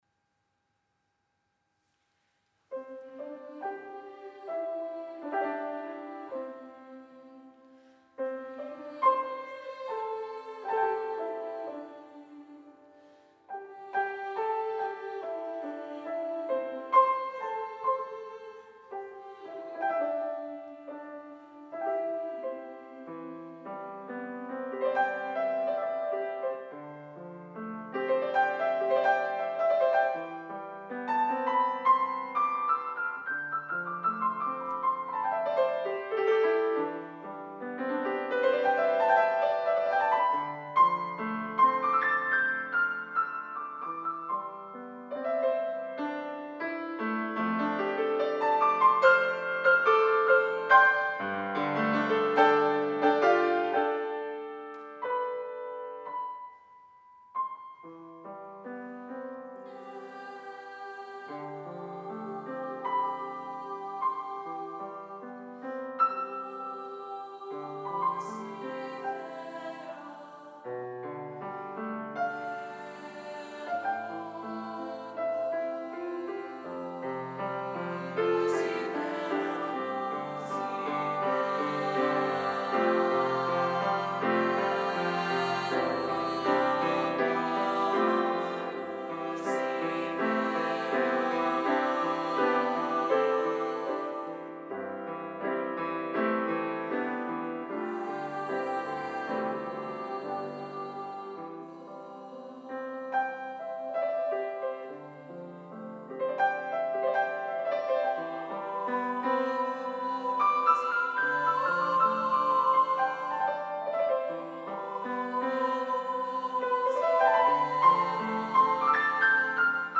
Suitable for female voices or advanced children.
SA choir (with divisi) with piano
live Demo